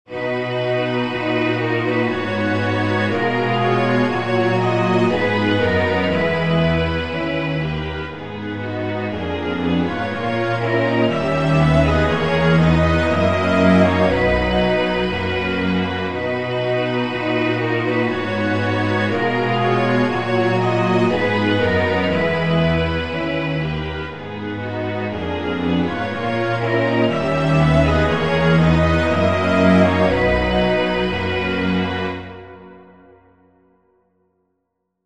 Groove in 12edo
12edo_groove.mp3